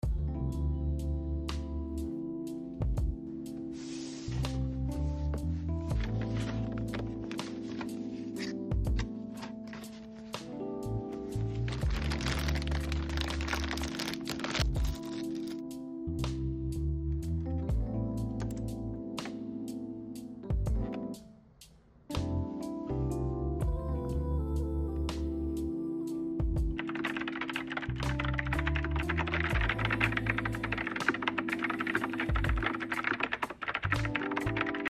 Vintage charm, wireless freedom, and keys with a soft, classic sound.